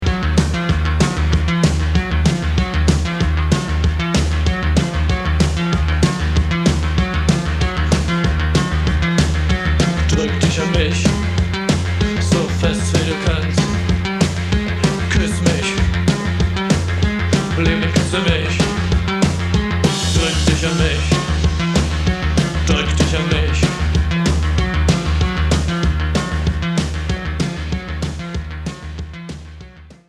Tanzbarer Minimalismus vom Feinsten.